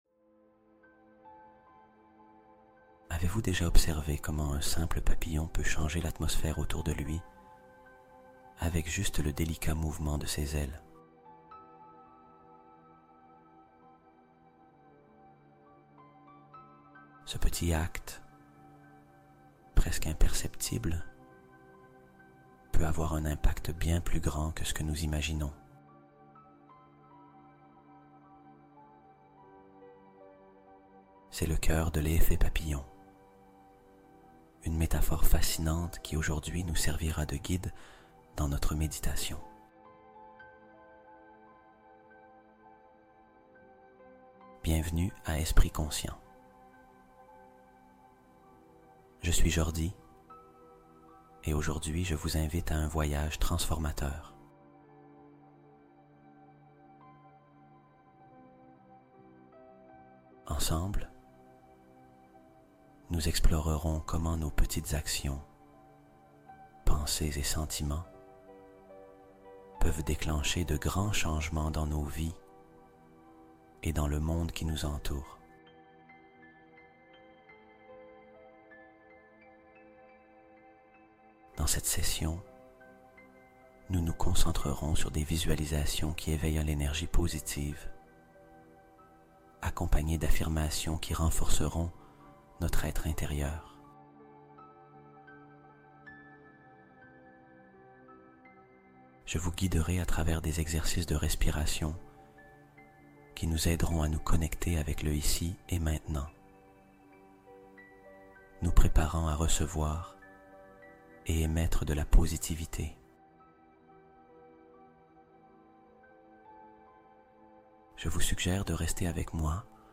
Méditation Guidée: Le podcast TU VOIS CECI?